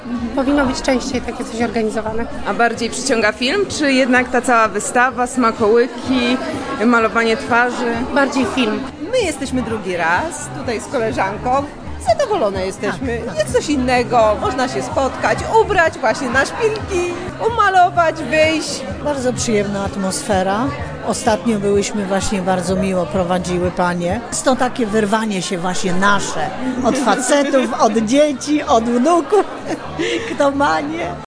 Posłuchaj wypowiedzi głogowianek: